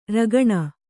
♪ ragaṇa